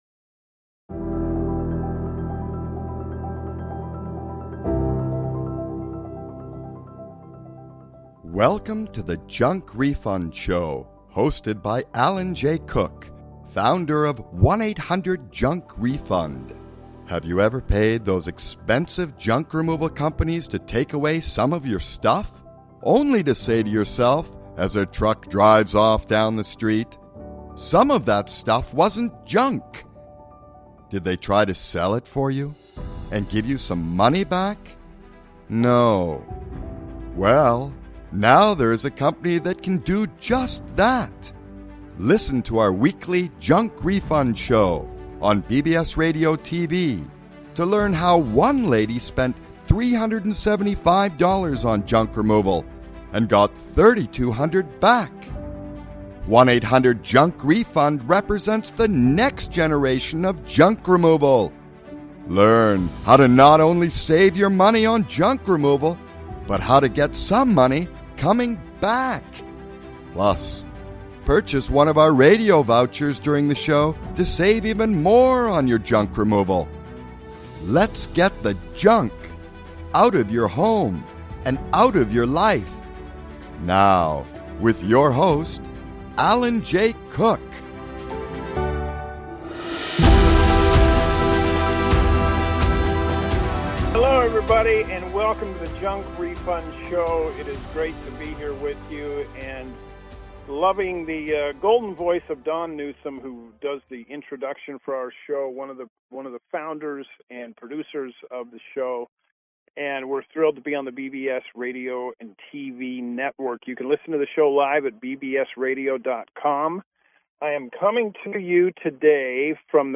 Live from Merriweather Post Pavilion in Columbia, Maryland